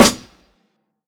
JSH_SNR.wav